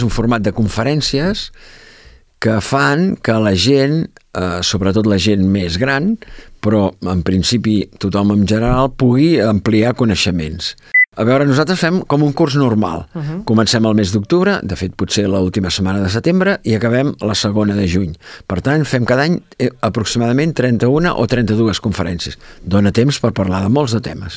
en una entrevista a Ràdio Calella TV